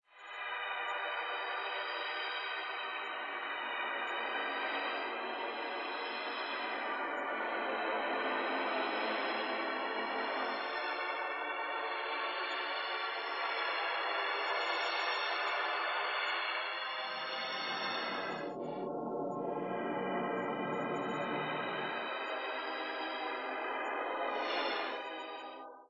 Здесь вы найдете как абстрактные шумы, так и более структурированные аудиоиллюзии.
Звук, передающий галлюцинации персонажа